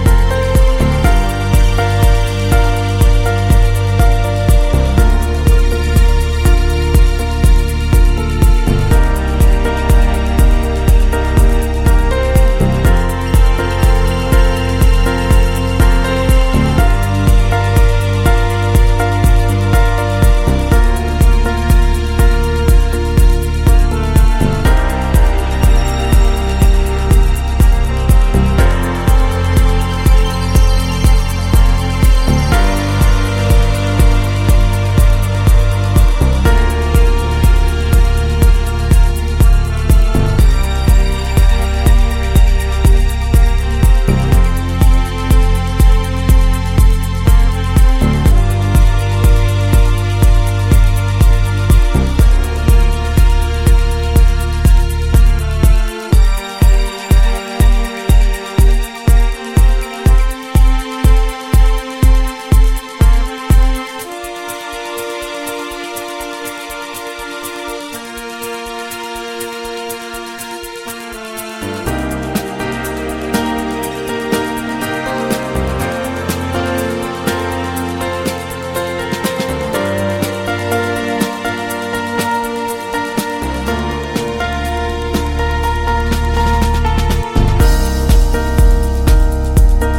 Styl: Progressive, House, Techno